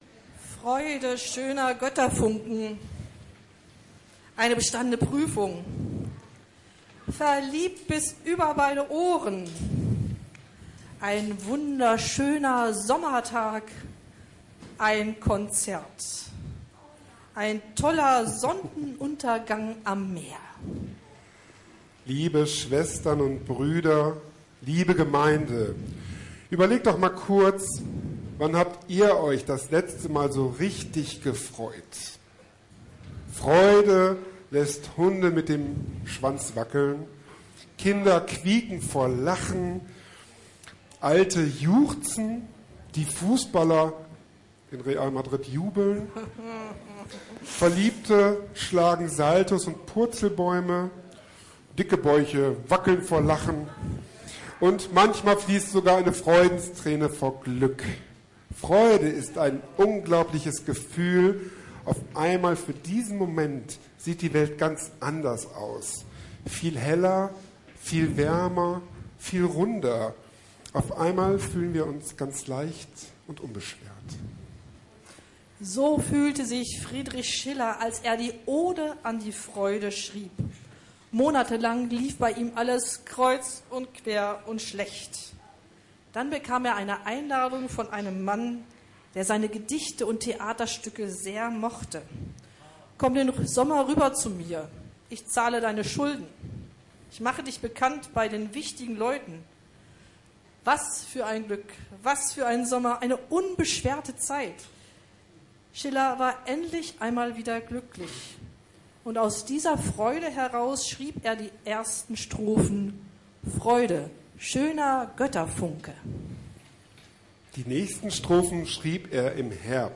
Es handelte sich um einen Gottesdienst in einfacher Sprache, daher ist es keine Predigt im klassischen Sinn.